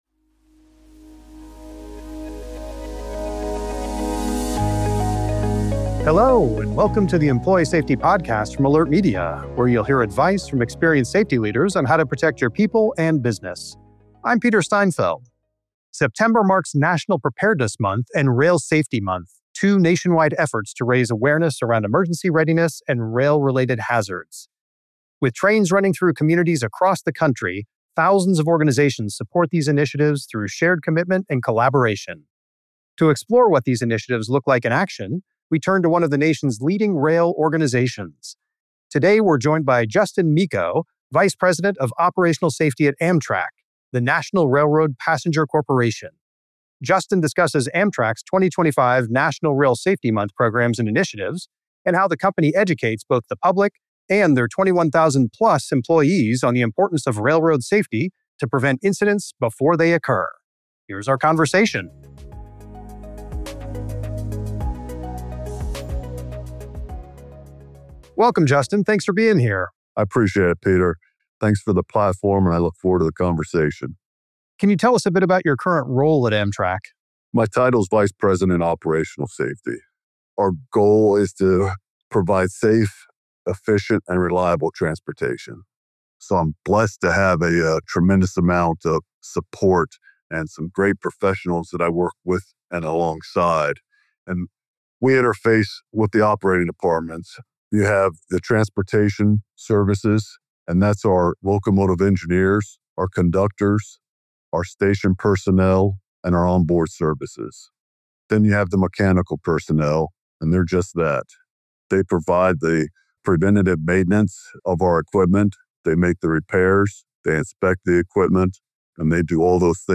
You can find this interview and many more by following The Employee Safety Podcast on Spotify or Apple Podcasts .